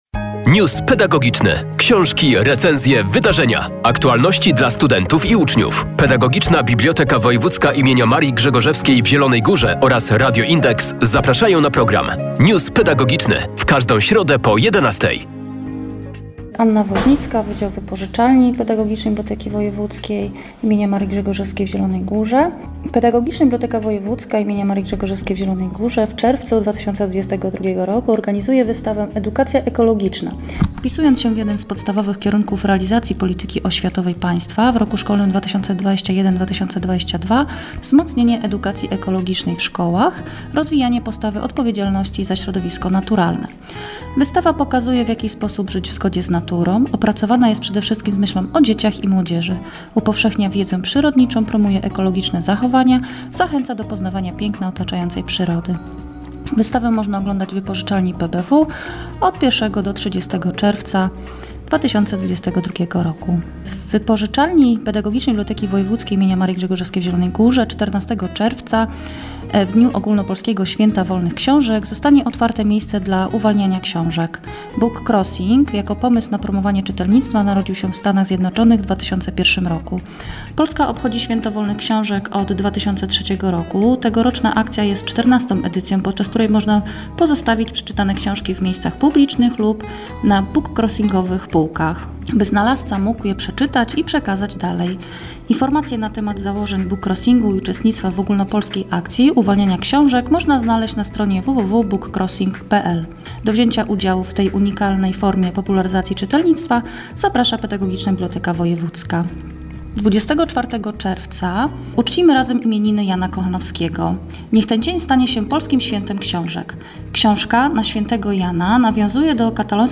News pedagogiczny.